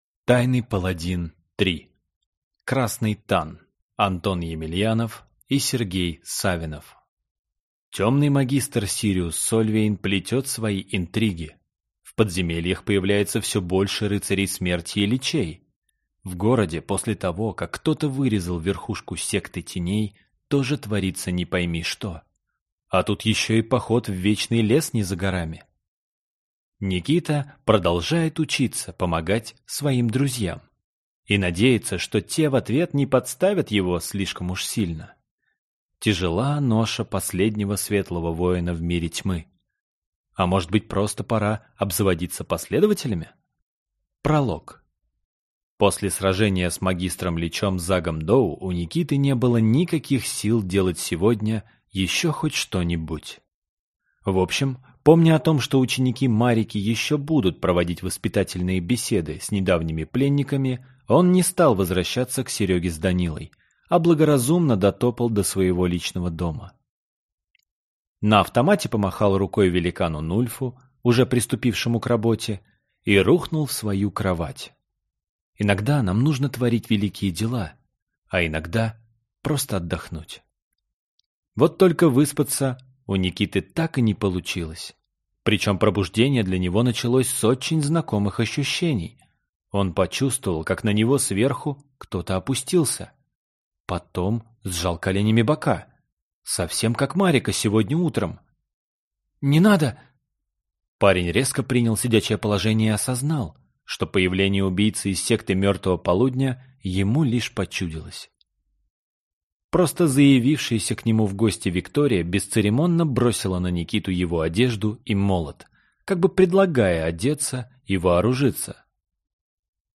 Аудиокнига Тайный паладин 3. Красный тан | Библиотека аудиокниг